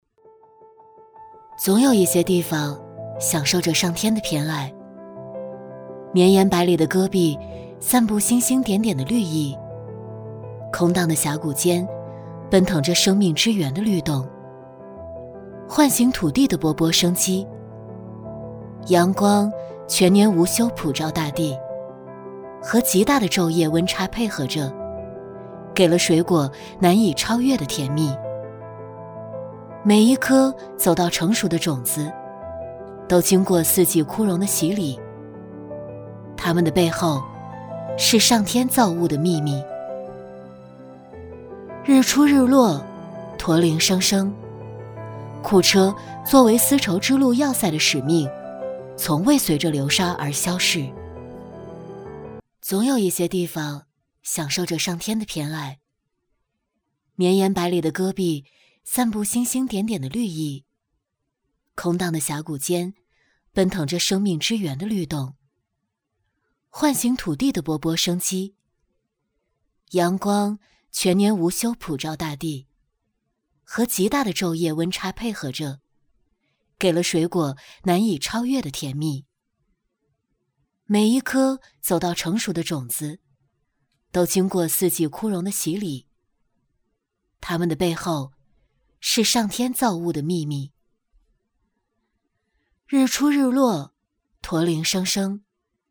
女49-品质广告库车初品
女49双语配音 v49
女49-品质广告库车初品.mp3